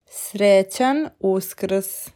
Srećan Uskrs (tap for pronunciation).